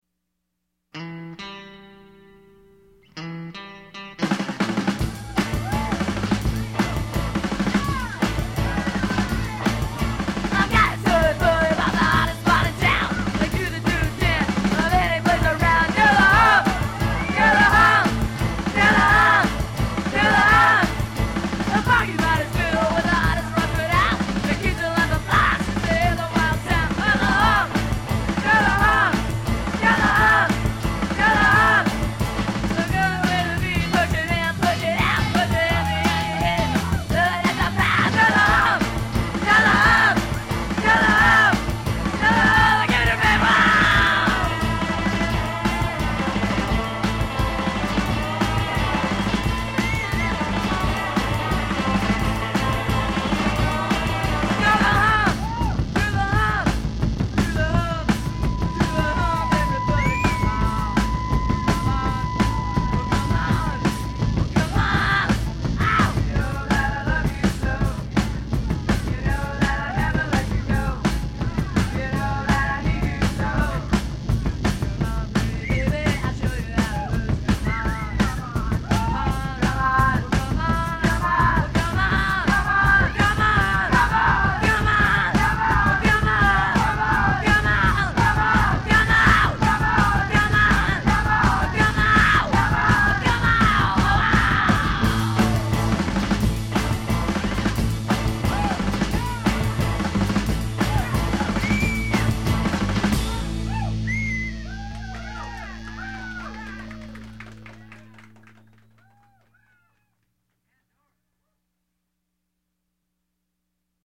girl group